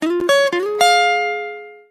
Гитара , Рингтоны без слов , Короткие рингтоны